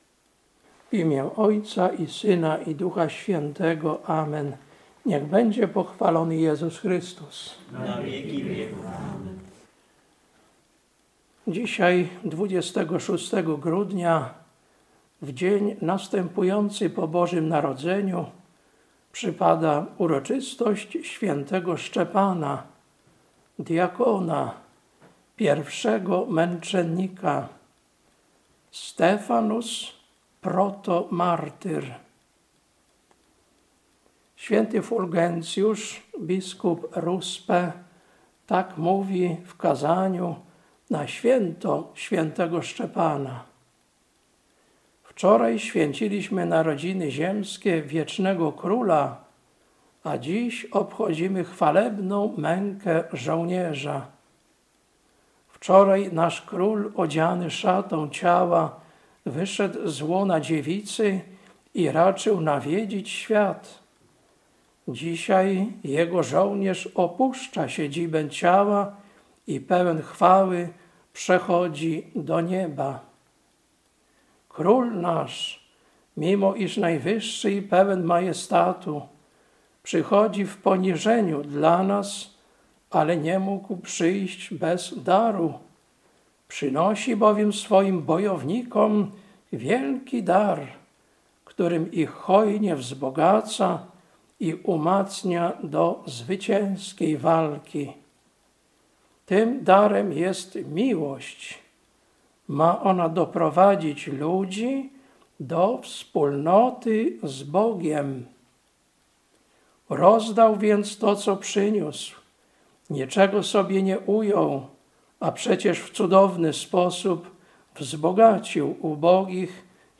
Kazanie na uroczystość Św. Szczepana, Pierwszego Męczennika, 26.12.2025 Lekcja: Dz 6, 8-10; 7, 54-59 Ewangelia: Mt 23, 34–39